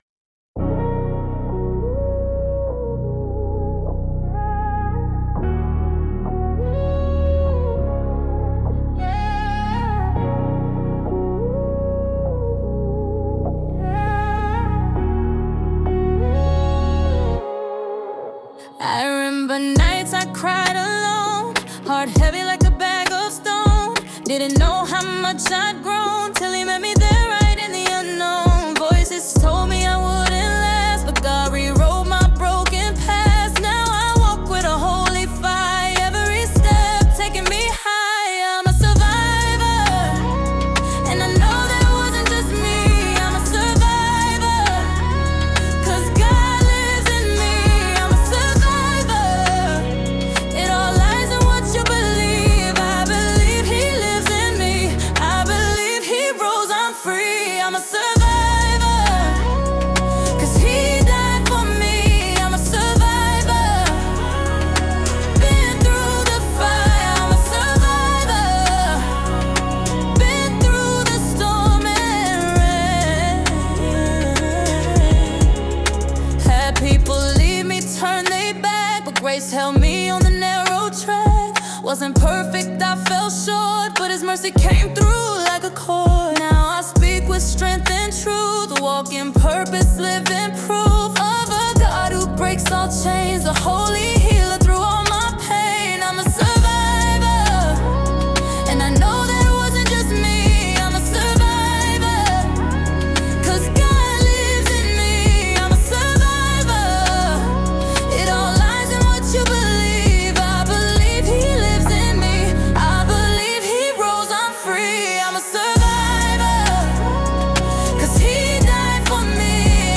Brand new music from up and coming Christian Artists.